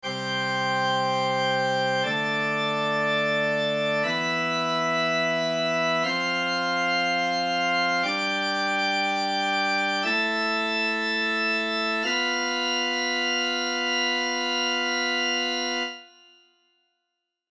Quintas-paralelas-1.mp3